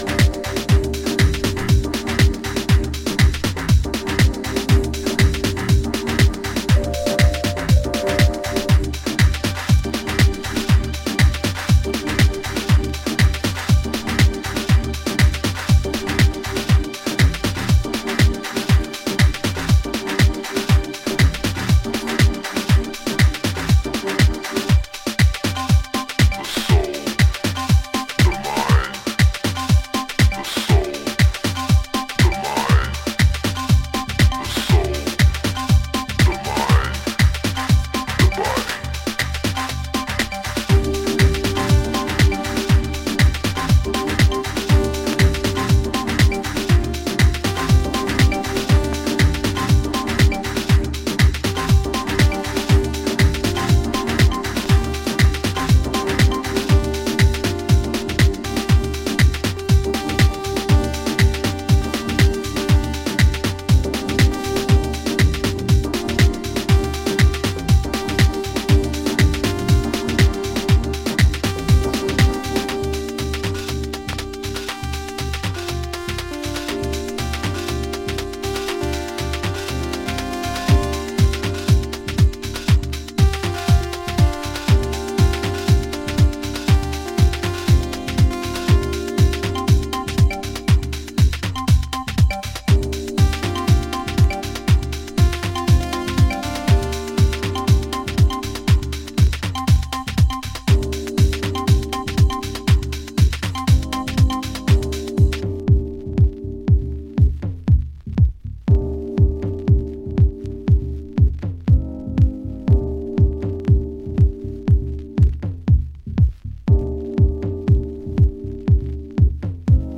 どれか1曲におすすめを絞るのが難しい、良質なハウスEPです。